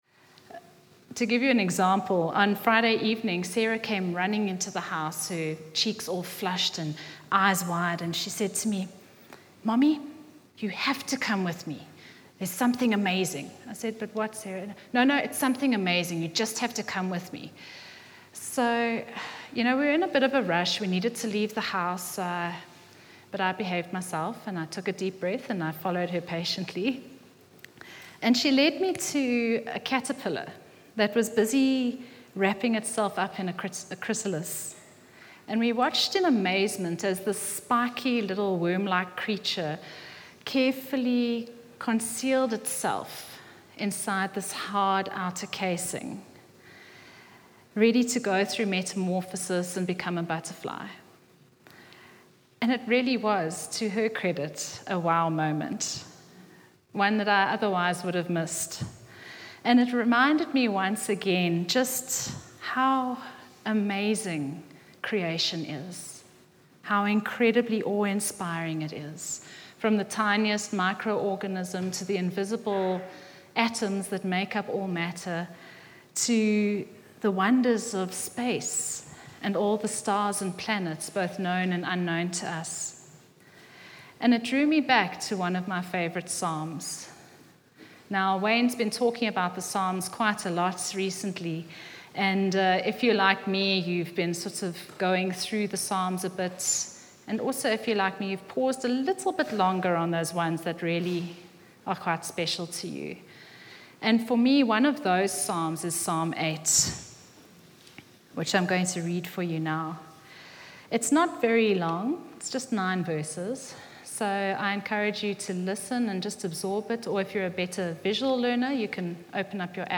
From Hillside Vineyard Christian Fellowship, at Aan-Die-Berg Gemeente. Johannesburg, South Africa.